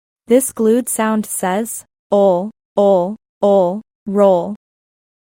OLL-roll-lesson-AI.mp3